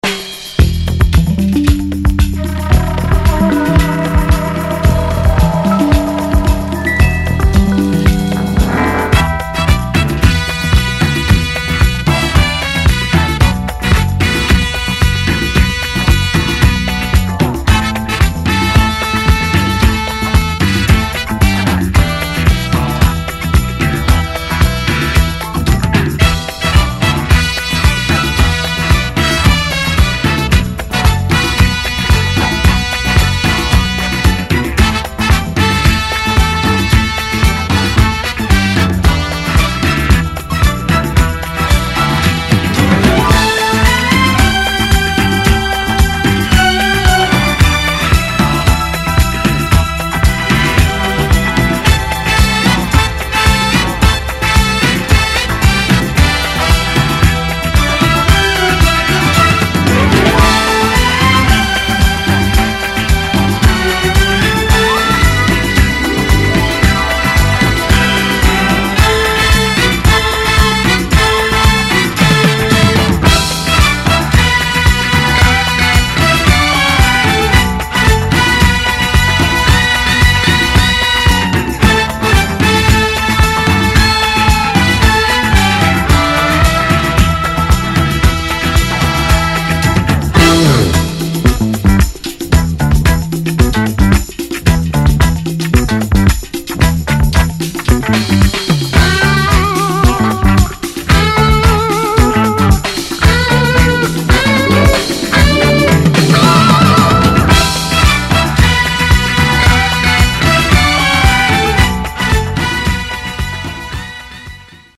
Funky Instrumental Disco